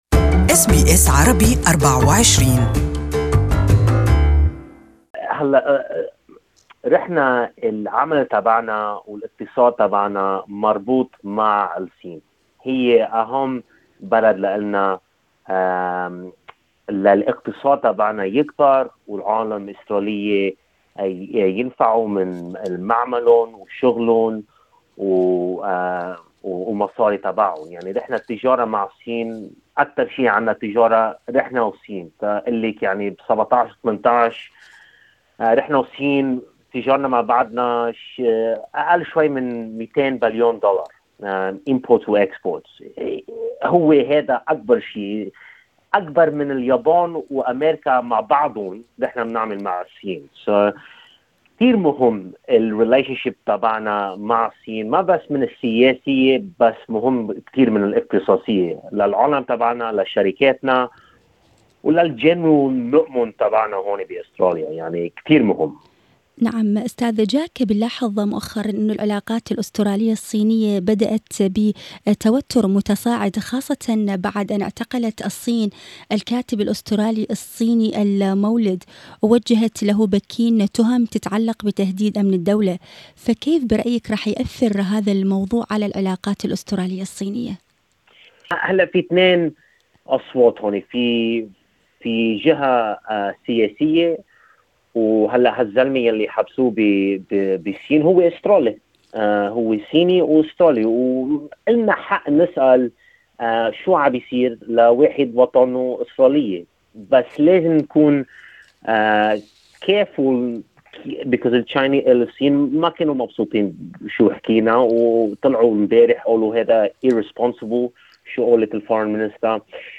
لقاءِ